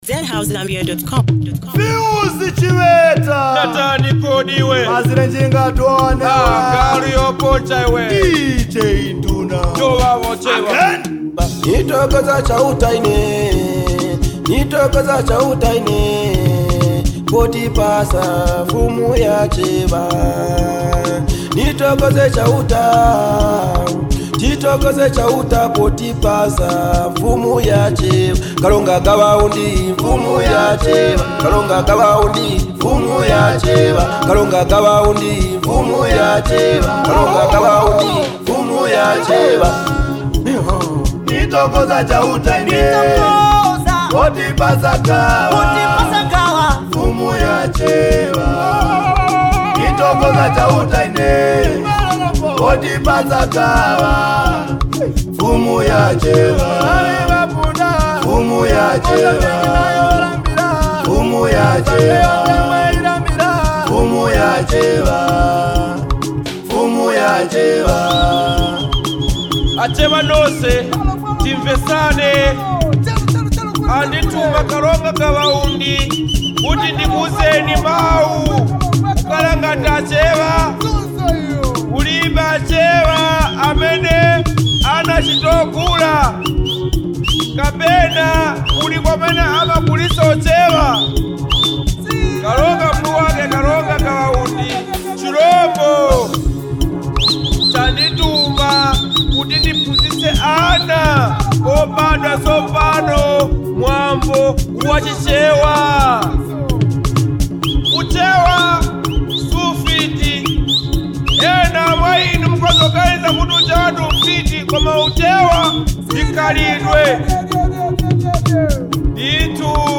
Chewa traditional song